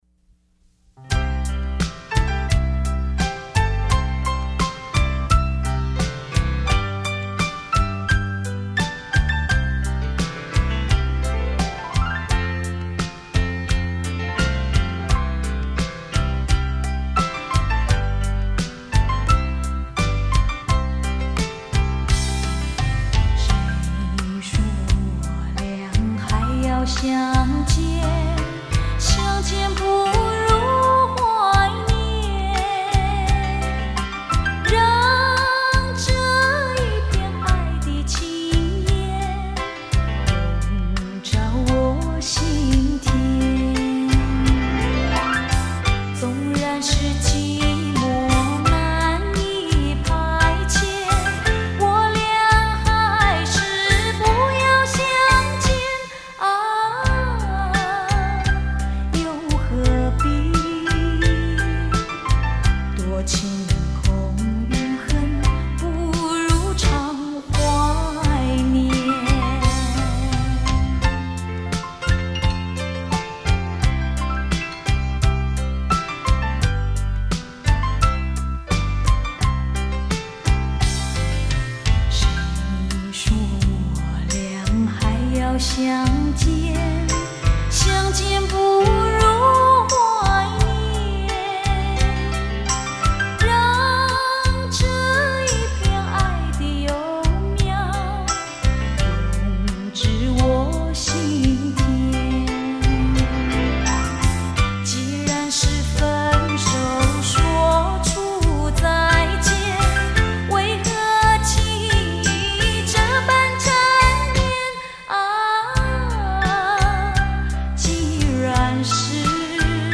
双钢琴现场演奏，熟悉好歌精选